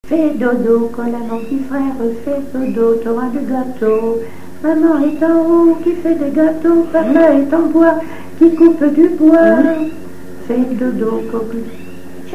berceuse
Pièce musicale inédite